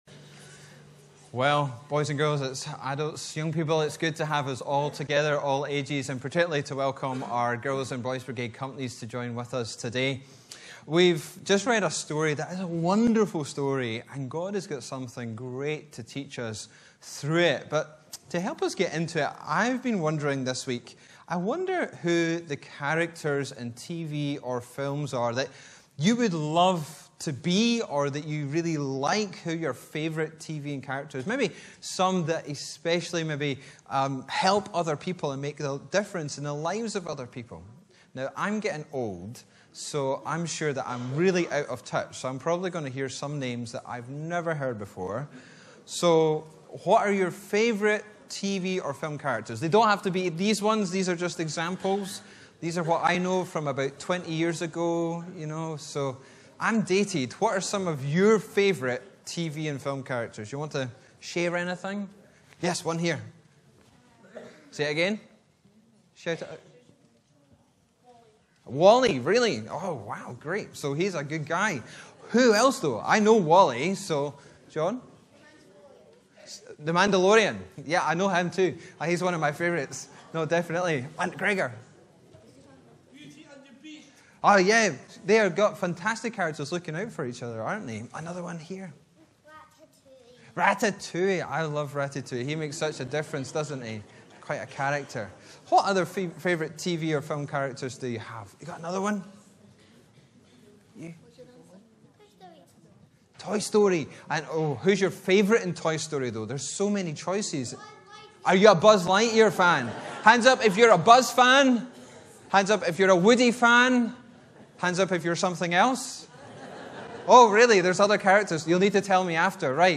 Sermons in this Series
Bible references: John 12:1-9 Location: Brightons Parish Church